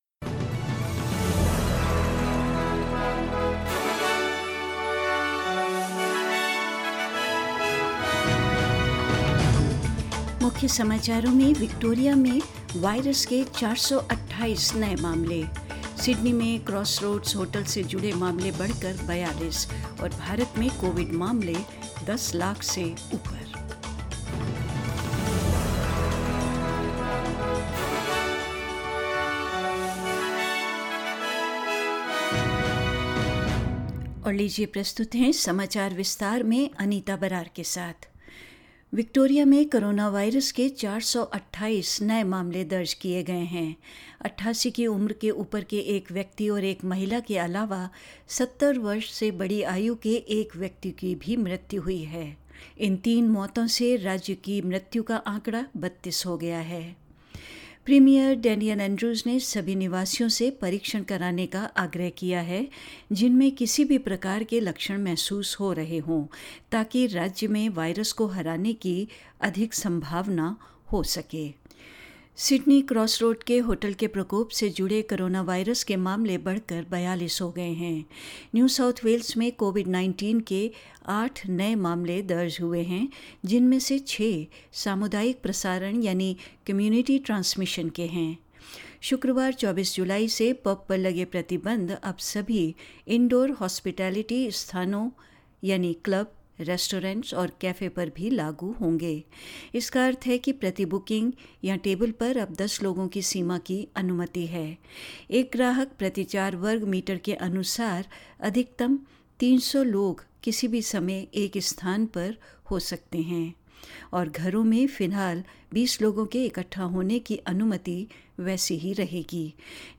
News in Hindi 17th July 2020